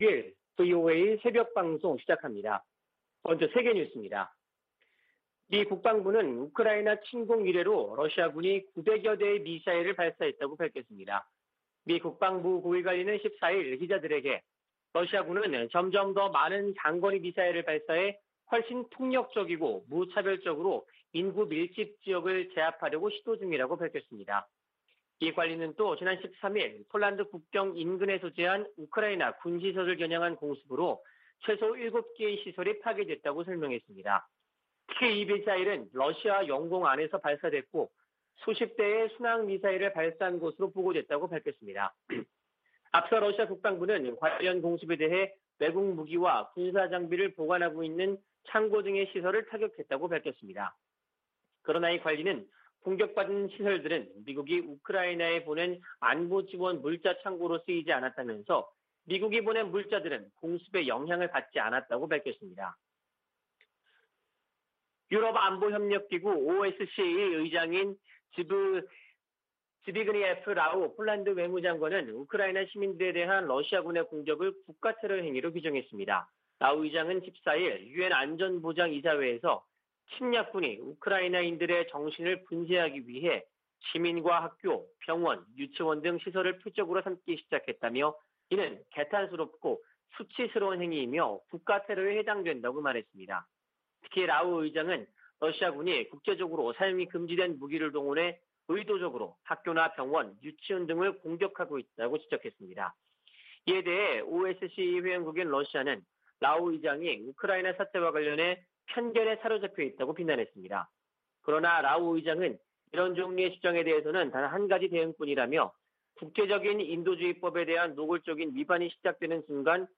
VOA 한국어 '출발 뉴스 쇼', 2022년 3월 16일 방송입니다. 북한이 이동식발사대(TEL)에서 미사일을 쏠 때 사용하는 콘크리트 토대를 순안공항에 증설한 정황이 포착됐습니다. 백악관은 북한의 신형 ICBM 발사가 임박했다는 보도와 관련해, 예단하지 않겠다고 밝혔습니다. 백악관 국가안보보좌관이 중국 고위 당국자와 만났습니다.